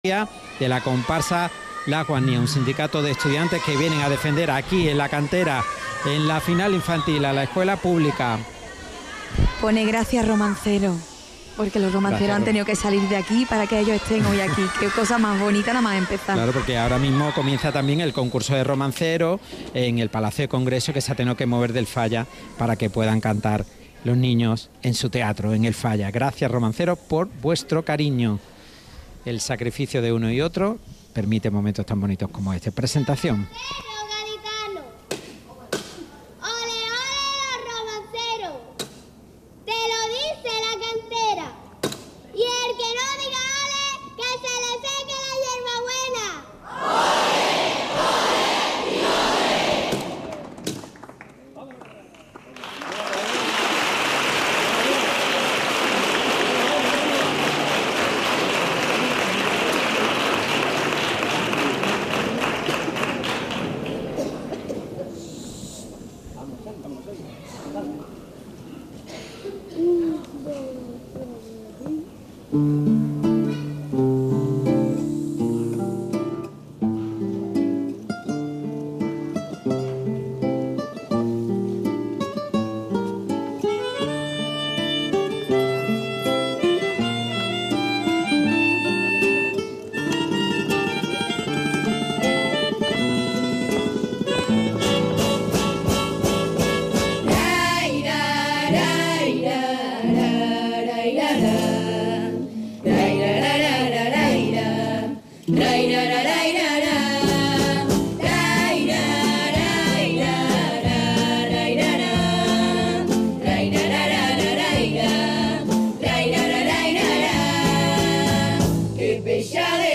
Comparsa Infantil - Las guasnías Final